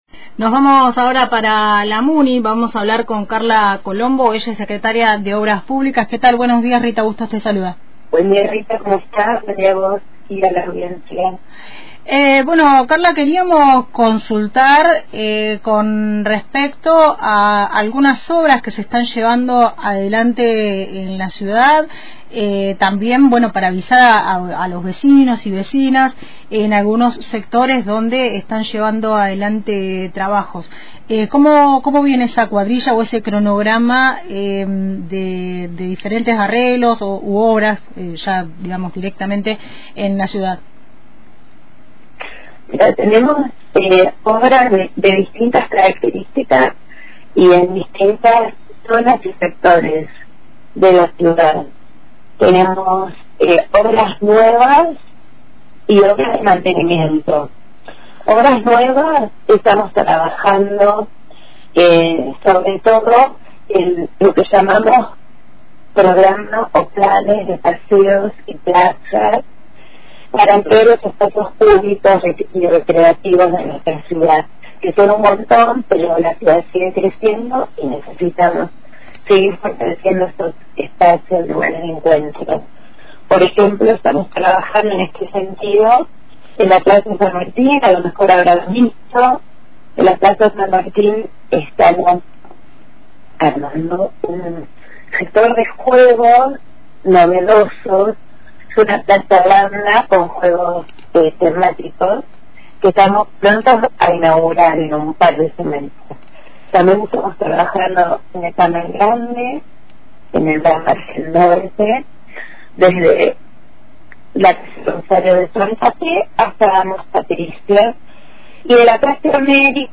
Carla Colombo, secretaria de Obras Públicas de la municipalidad de General Roca, e detalló los proyectos de obras y mantenimiento que lleva adelante el municipio.